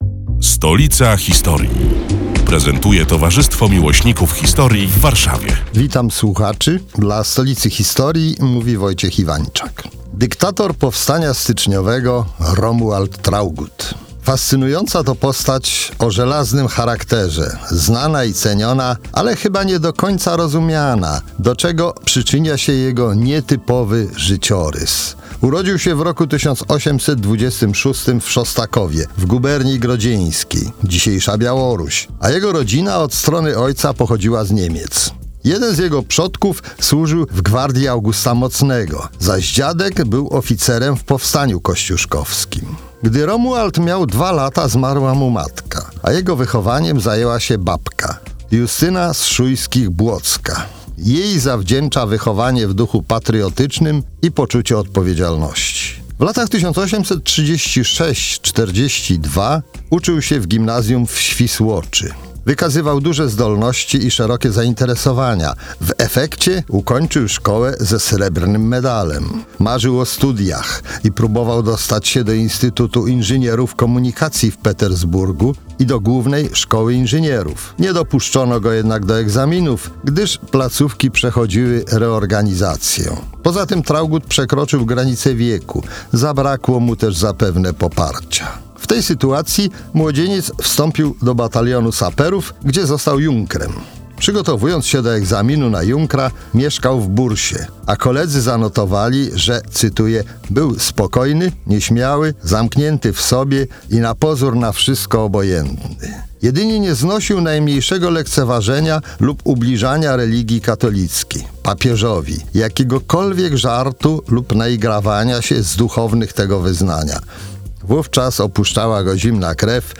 137. felieton pod wspólną nazwą: Stolica historii. Przedstawiają członkowie Towarzystwa Miłośników Historii w Warszawie, które są już od trzech lat emitowane w każdą sobotę, w nieco skróconej wersji, w Radiu Kolor.